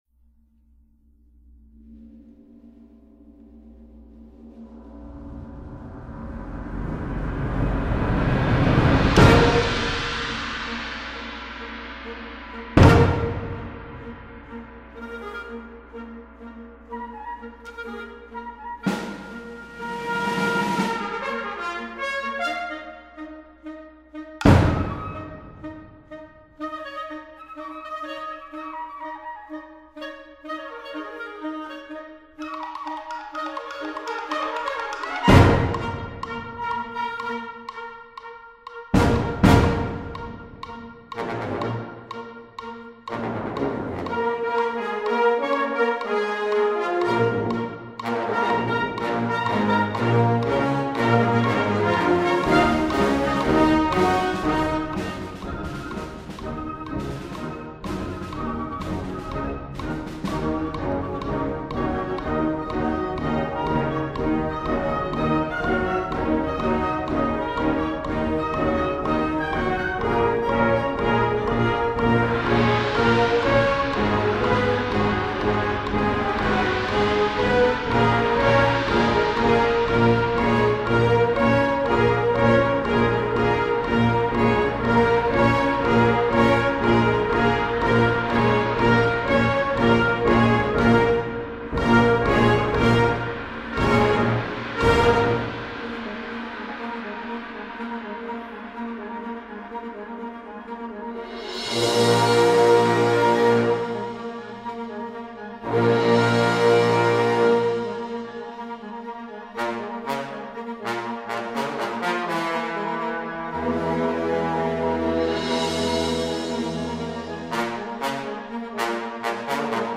Voicing: Band